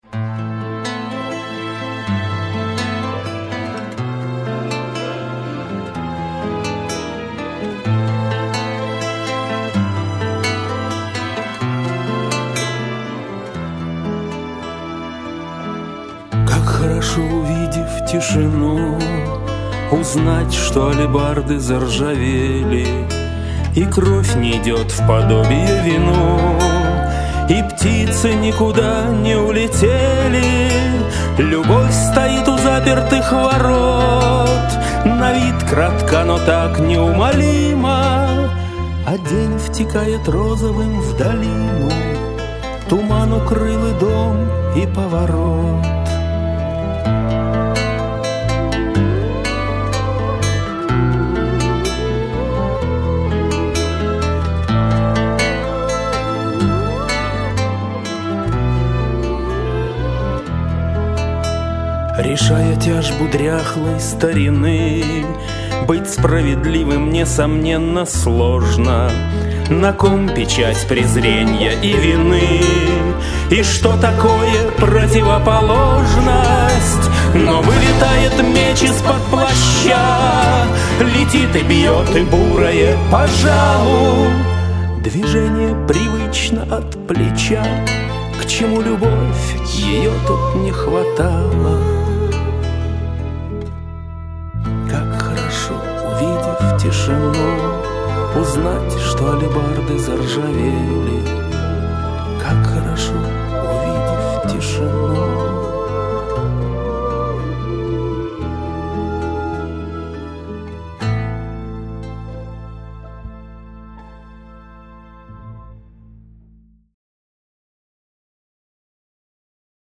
авторская песня
Исполняет автор